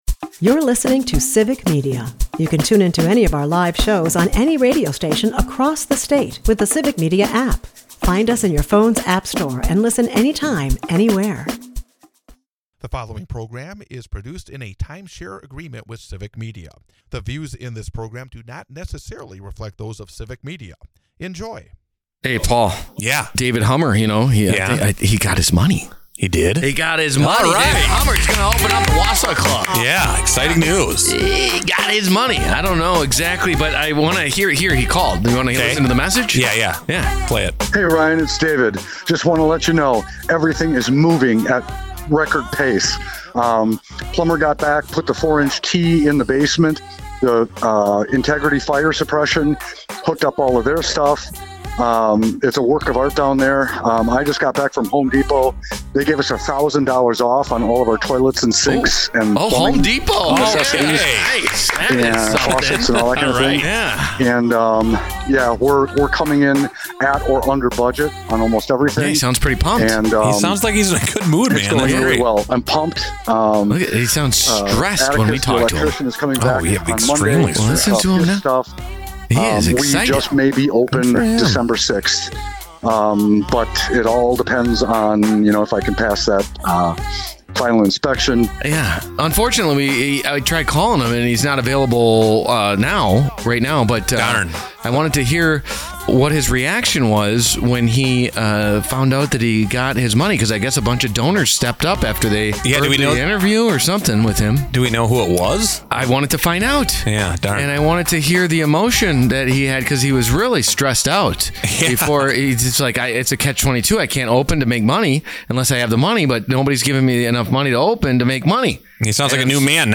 Plus, a Hy-Vee Wausau Business Rumor, and we hear from the final finalist for $10,000 in the Friend of a Friend Competition! The Wausau Business Show is a part of the Civic Media radio network and airs Saturday from 8-9 am on WXCO in Wausau, WI.